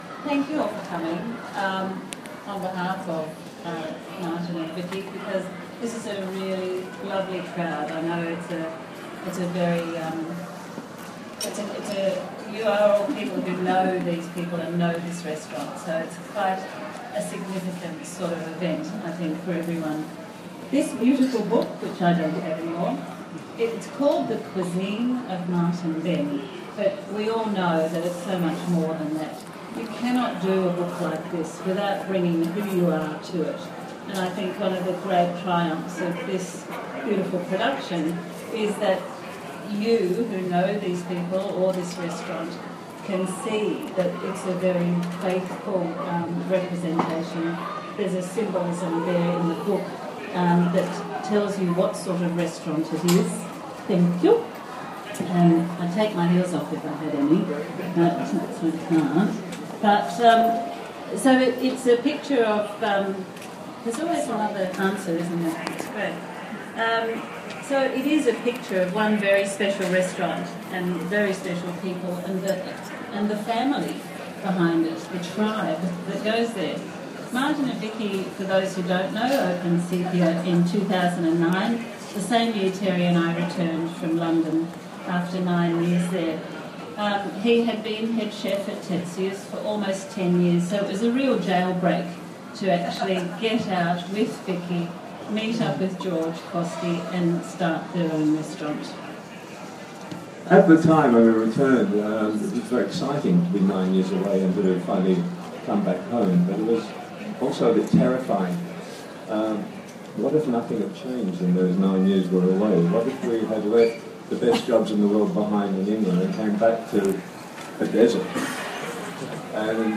Sepia Book Launch
Introduction by Jill Dupleix & Terry Durack